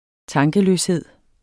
Udtale [ ˈtɑŋgəløsˌheðˀ ]